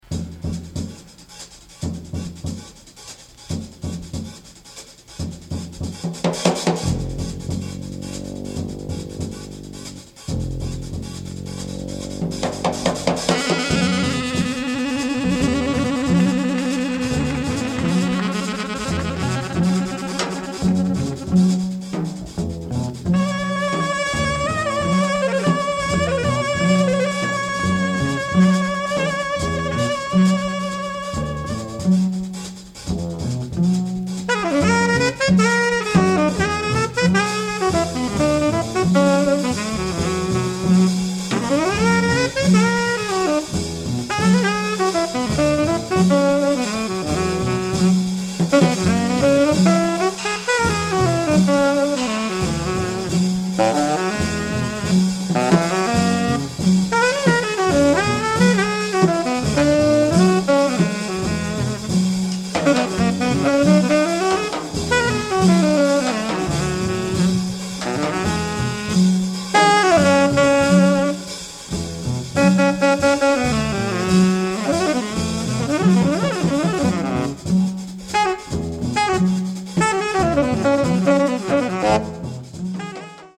Recorded live at the Village Vangaurd , November 1985.